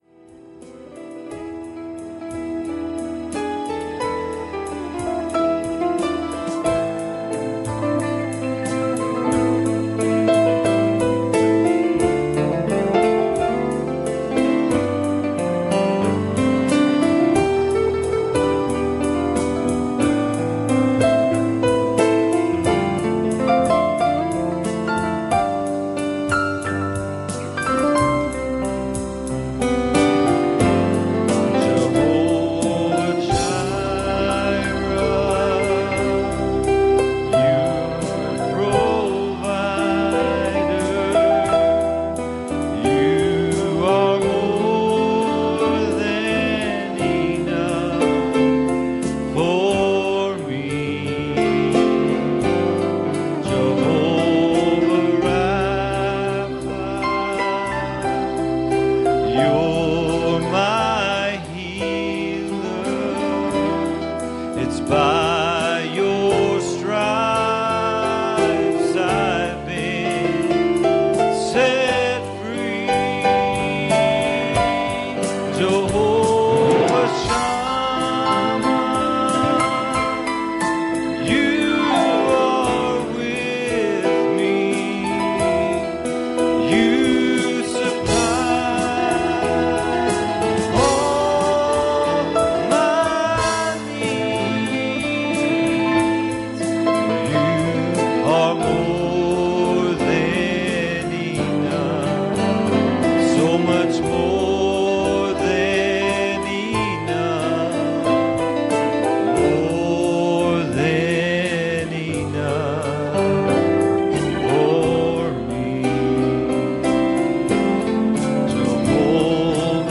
Matthew 14:30 Service Type: Sunday Morning "Here we are.